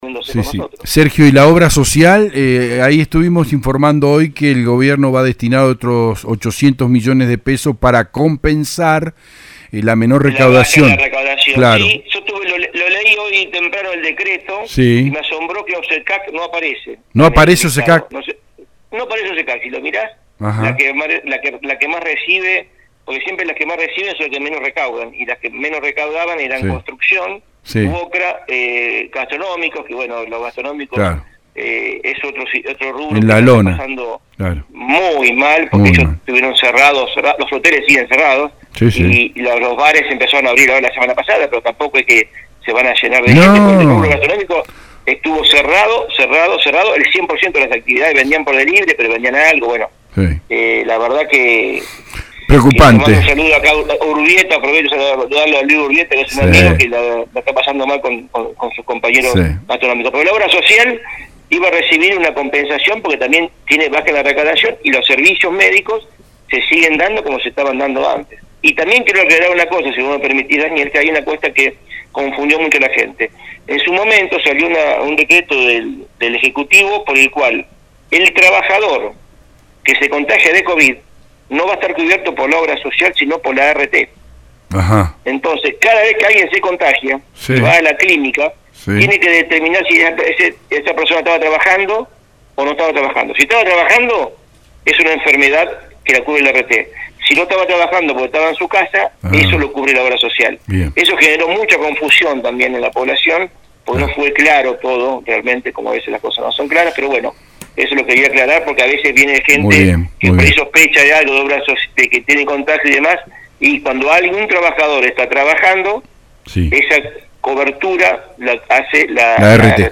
en el programa Con Zeta de radio EL DEBATE